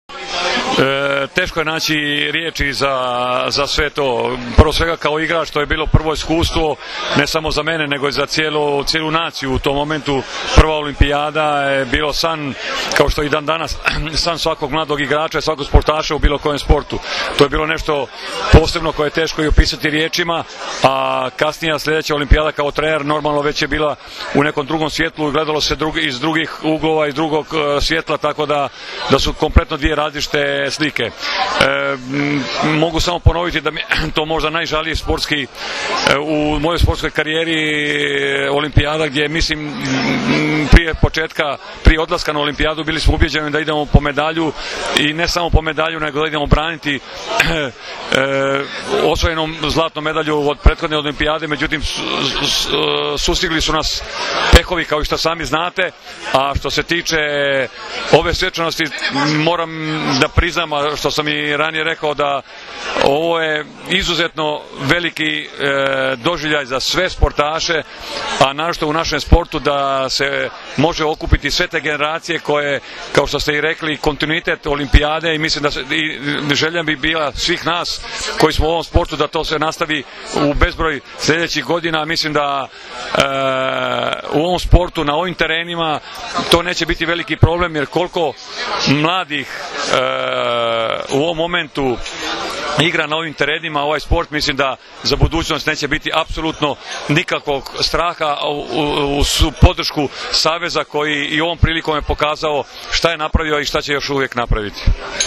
Odbojkaški savez Srbije je večeras u beogradskom hotelu „M“ priredio svečanost pod nazivom „Olimpijski kontinutitet“ povodom plasmana ženske i muške seniorske reprezentacije na Olimpijske igre u Londonu.
IZJAVA